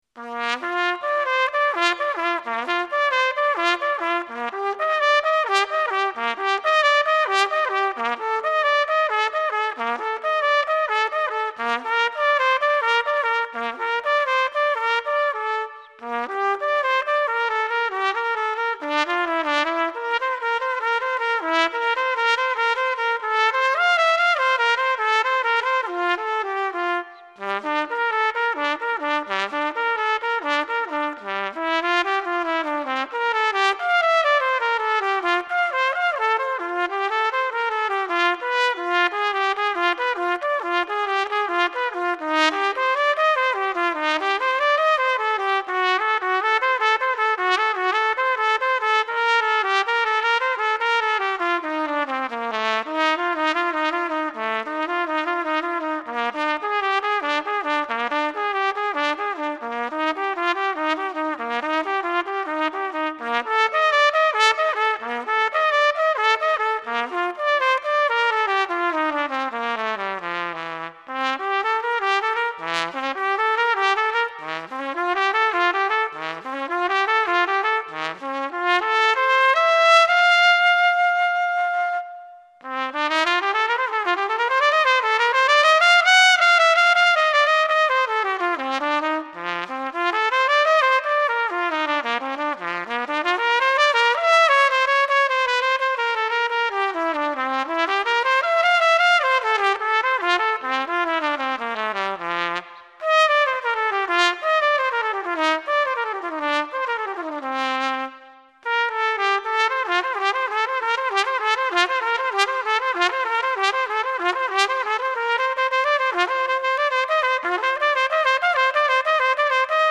Roba vecchia e di pessima qualità ma è una testimonianza storica di come suonavo qualche anno fa.
E ho suonato anche il fa pedale... Eheh